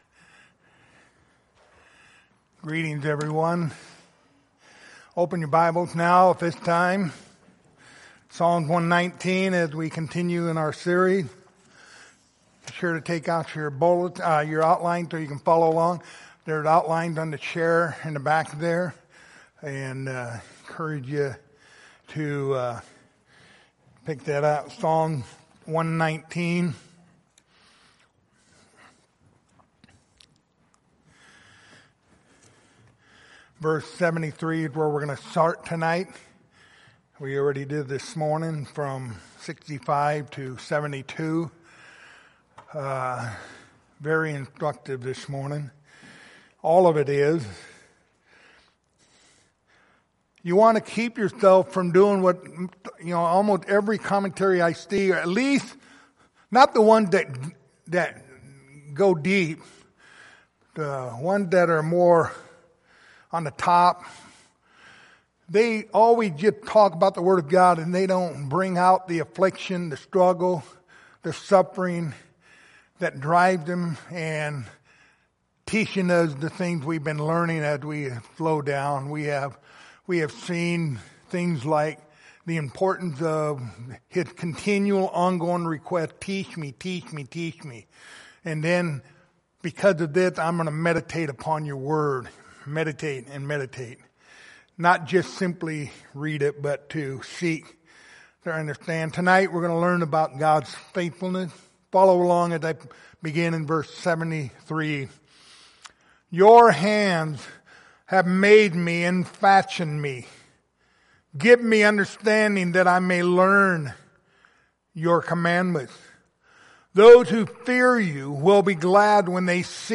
Psalm 119 Passage: Psalms 119:73-80 Service Type: Sunday Evening Topics